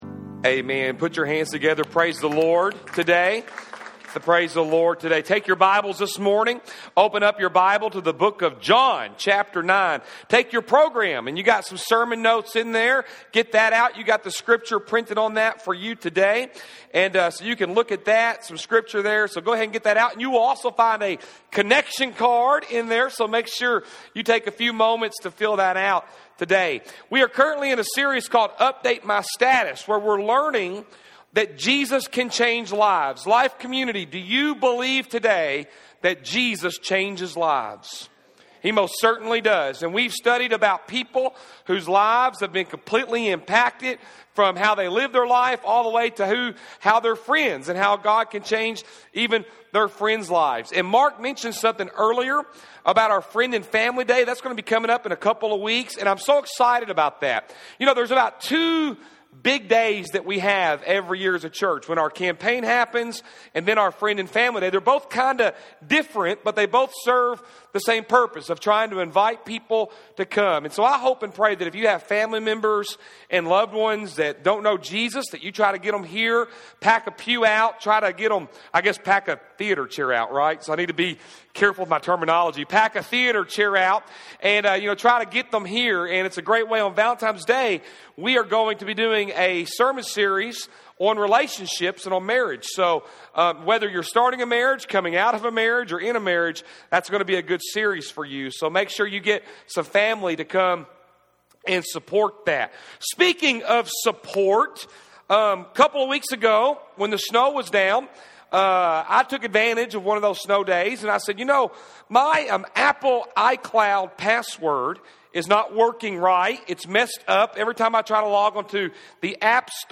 January 31, 2016 Update My Status- Support Service Type: Sunday AM | Fourth message in the series “Update My Status” featuring the healing of the man born blind in John 9.